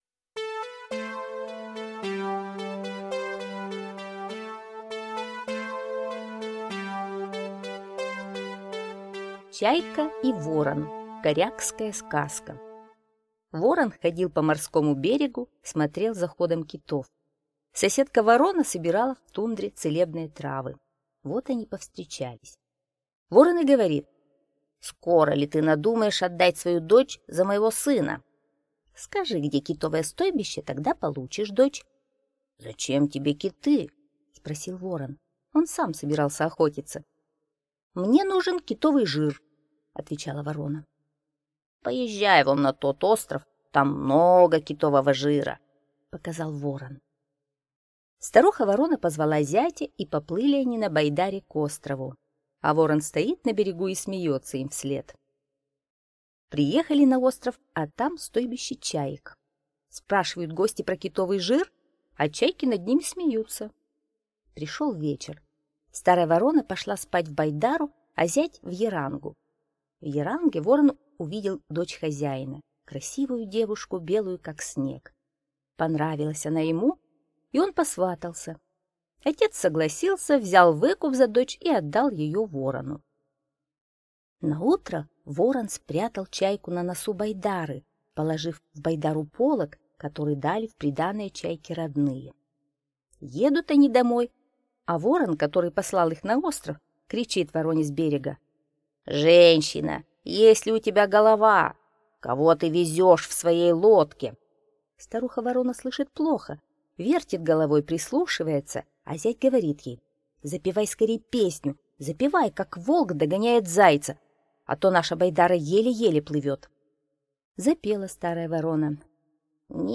Чайка и ворон - корякская аудиосказка - слушать онлайн